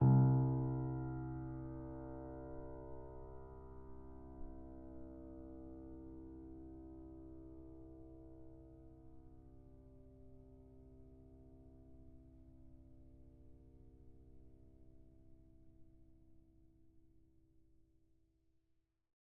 Salamander Grand Piano V3 OGG samples